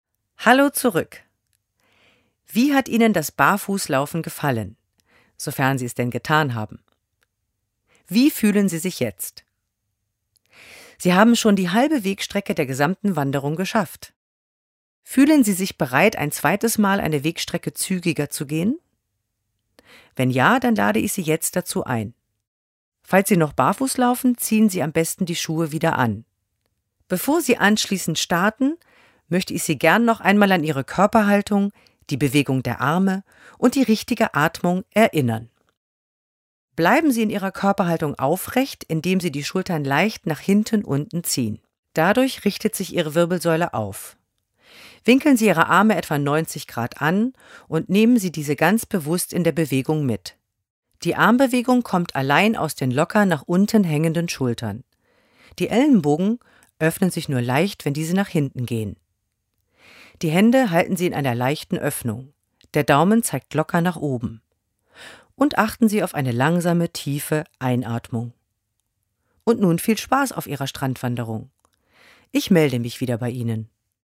Audioguide
Sprecherin für die deutsche Audiotour